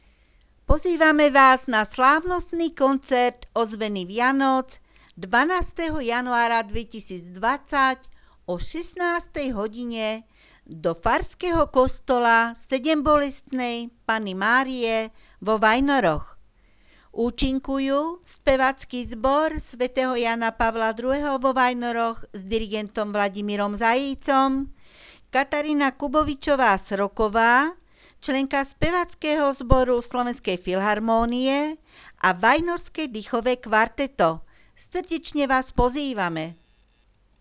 ozveny_vianoc_koncert_pozvanka.wav